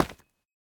Minecraft Version Minecraft Version latest Latest Release | Latest Snapshot latest / assets / minecraft / sounds / block / calcite / step1.ogg Compare With Compare With Latest Release | Latest Snapshot
step1.ogg